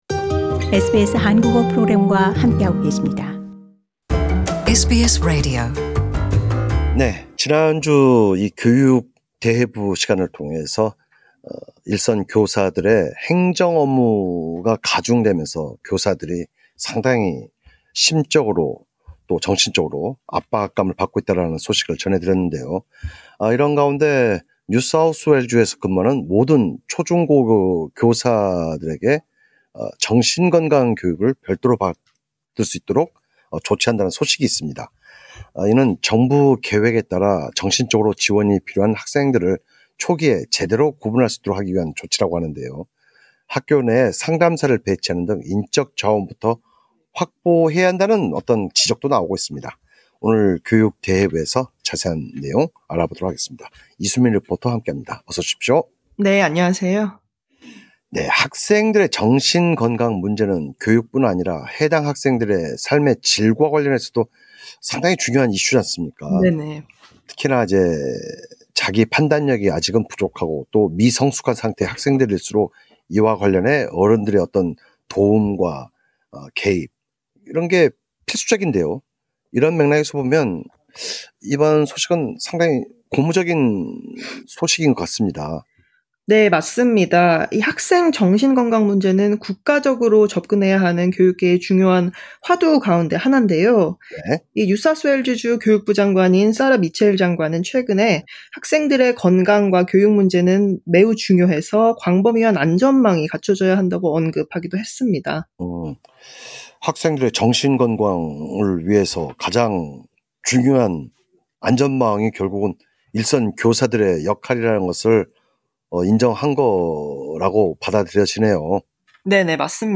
오늘 교육대해부에서 자세한 내용 함께 이야기 나누어 보도록 하겠습니다.